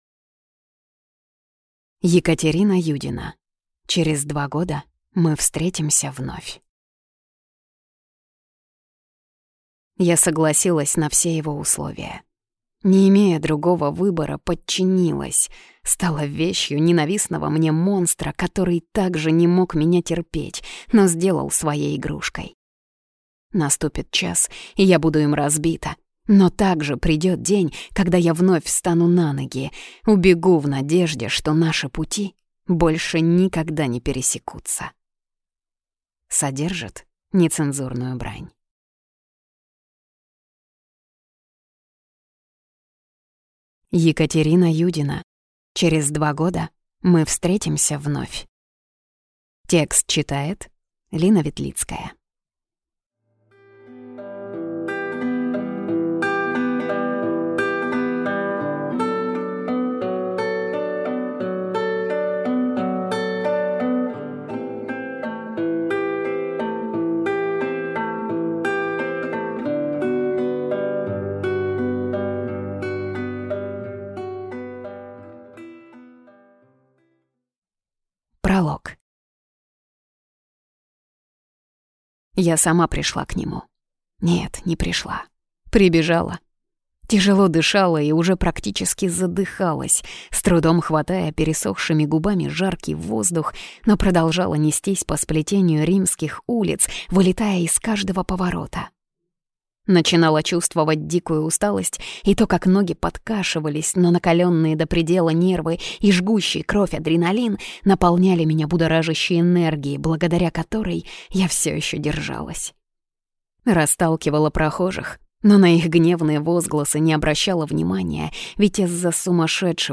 Аудиокнига Через два года мы встретимся вновь…